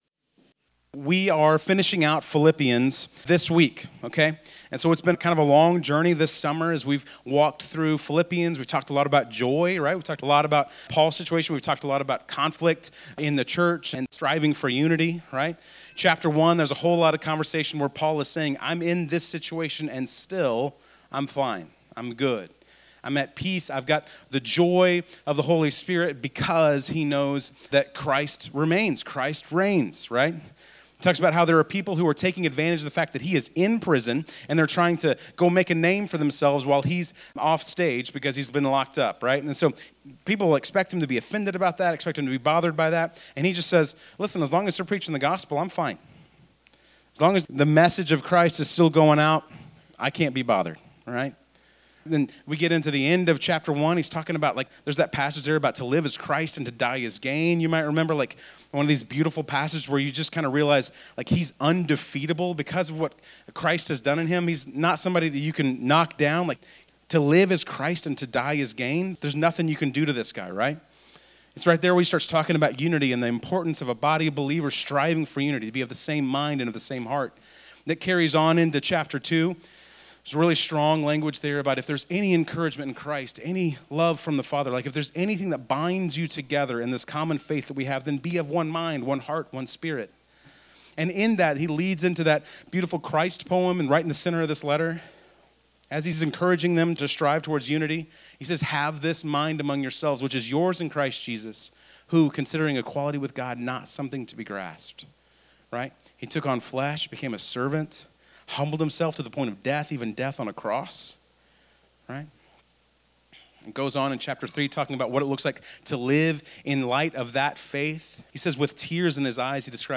Bible Text: Philippians 4:14-23 | Preacher